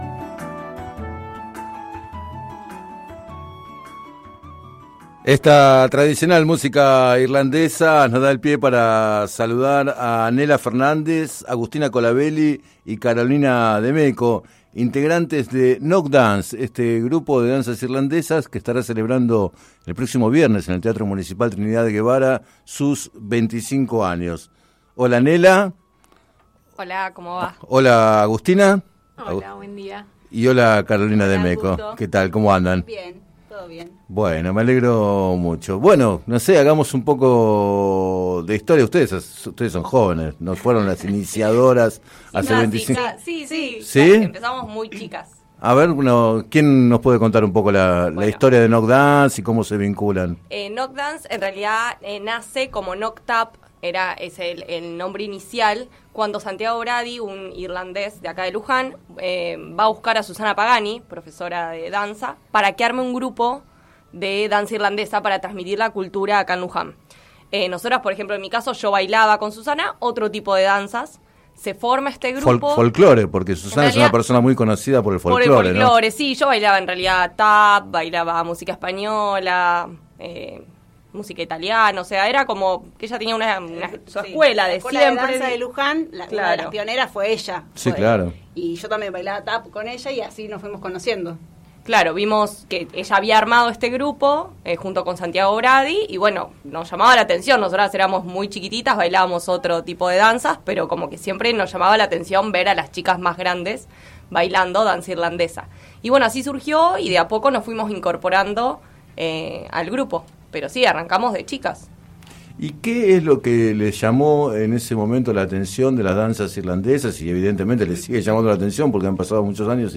Entrevistadas